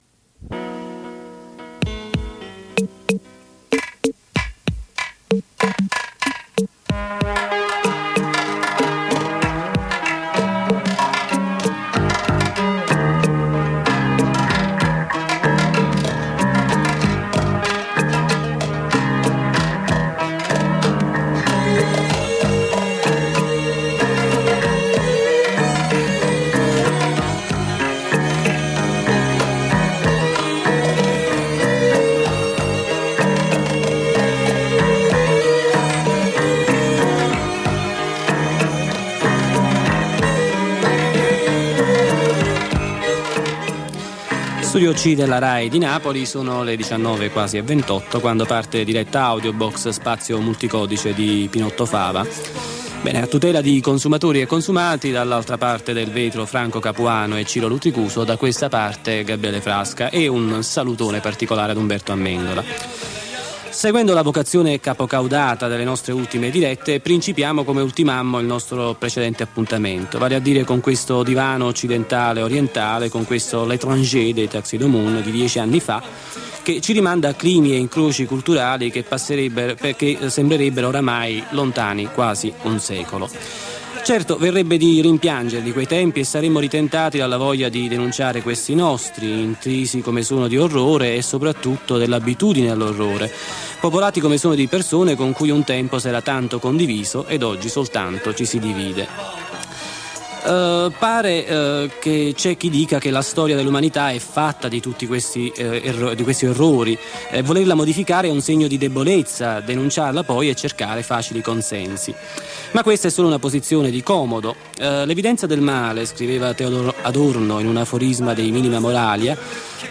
Estratti dalle dirette del 6, 8, 13, 15, 20, 22 febbraio 1991